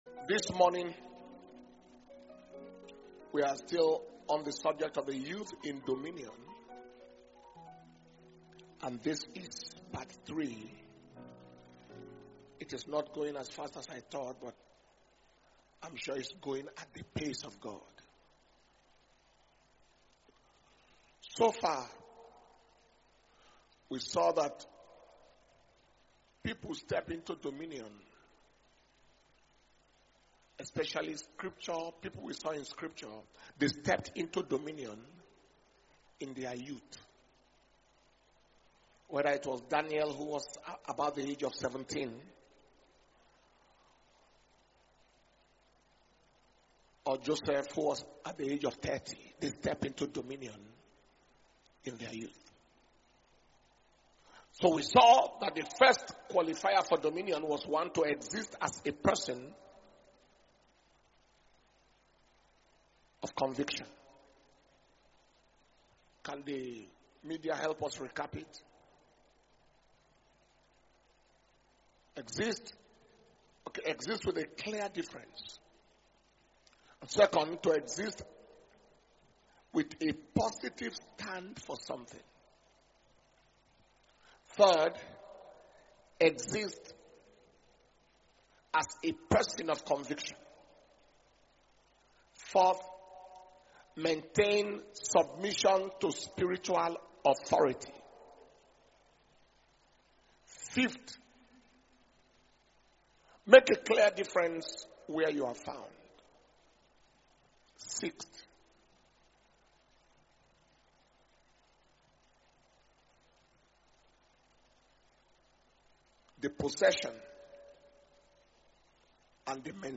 Dunamis Ignite Conference August 2025 – Day three Morning Session